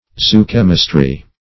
Search Result for " zoochemistry" : The Collaborative International Dictionary of English v.0.48: Zoochemistry \Zo`o*chem"is*try\, n. [Zoo- + chemistry.]